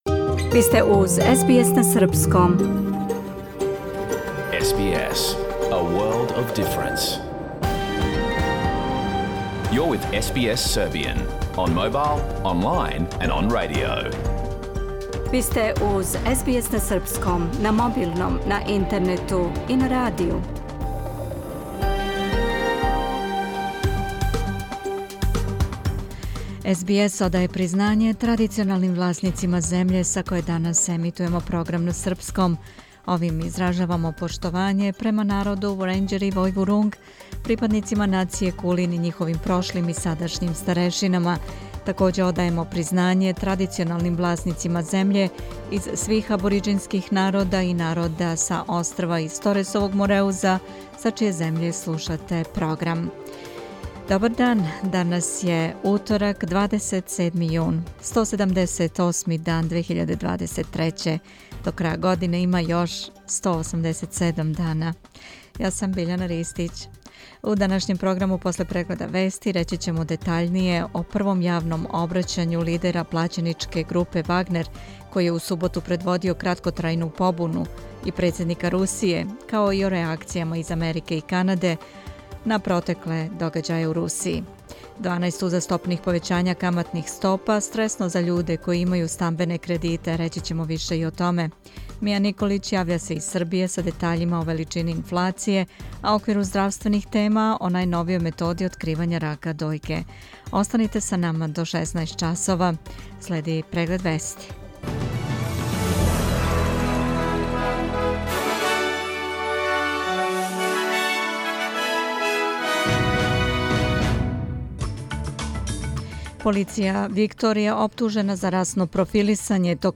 Програм емитован уживо 27. јуна 2023. године
Уколико сте пропустили данашњу емисију, можете је послушати у целини као подкаст, без реклама.